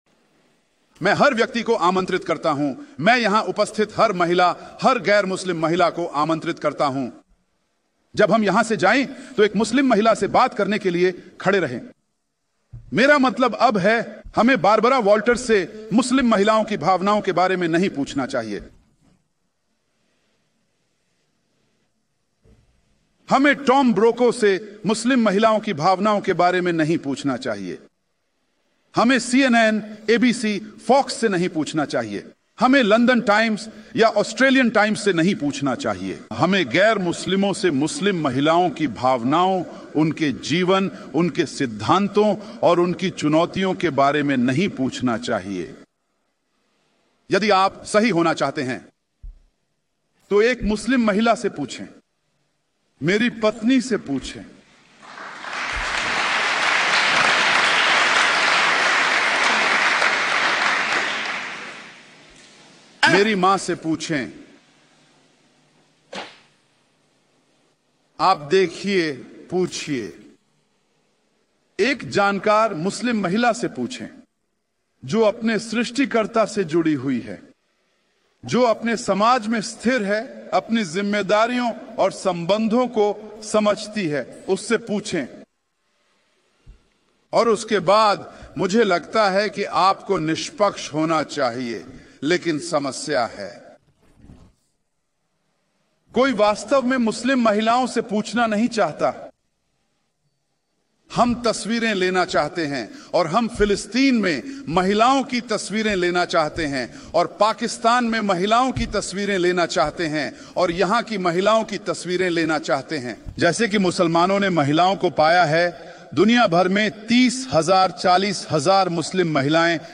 लेक्चर की खूबसूरत क्लिप है। उन्होंने इस्लाम में महिलाओं पर हो रहे अत्याचार के मुद्दे को निष्पक्ष रूप से उठाया है, और आंकड़ों के आधार पर अपनी बात साबित की है!